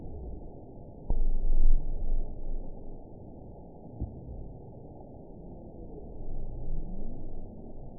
event 920969 date 04/21/24 time 00:13:05 GMT (1 year, 1 month ago) score 8.05 location TSS-AB05 detected by nrw target species NRW annotations +NRW Spectrogram: Frequency (kHz) vs. Time (s) audio not available .wav